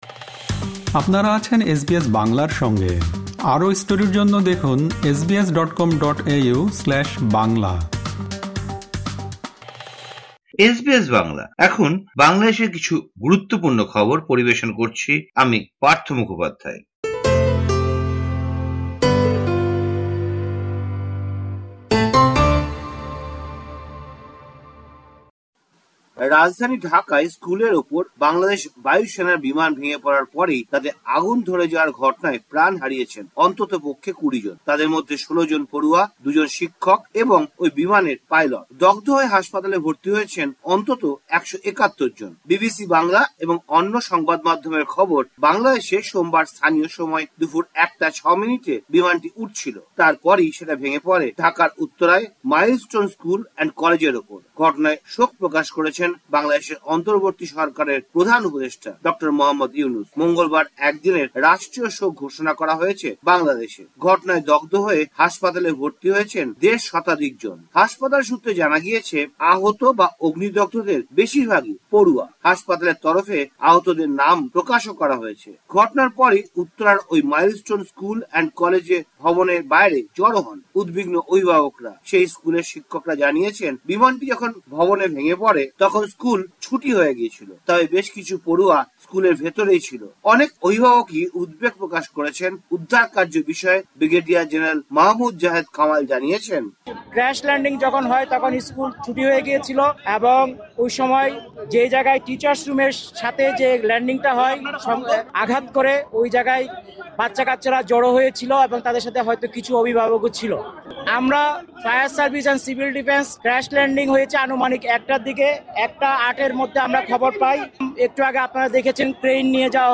সম্পূর্ণ প্রতিবেদনটি শুনতে উপরের অডিও-প্লেয়ারটিতে ক্লিক করুন।